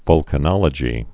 (vŭlkə-nŏlə-jē)